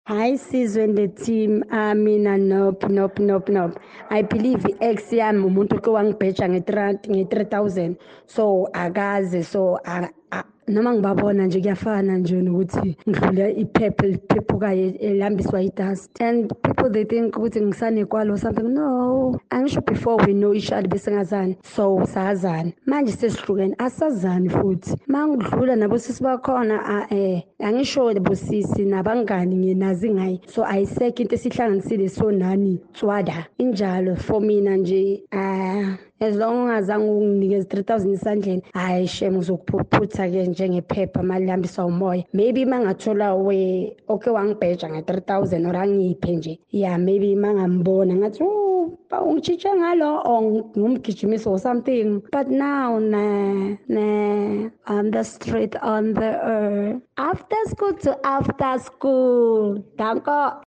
Here’s how Kaya Drive listeners felt about comparisons with their ex’s new partner: